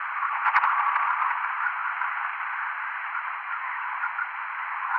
Tremor
“rumblings" from a slow release of acoustic/seismic energy and can sound, for example, like a train darting over train tracks. Here are a couple of examples of how tremor signals can sound.